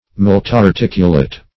Search Result for " multarticulate" : The Collaborative International Dictionary of English v.0.48: Multarticulate \Mul`tar*tic"u*late\, a. [Mult- + articulate.]